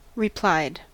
Ääntäminen
Vaihtoehtoiset kirjoitusmuodot (vanhahtava) replyed Ääntäminen US Haettu sana löytyi näillä lähdekielillä: englanti Käännöksiä ei löytynyt valitulle kohdekielelle.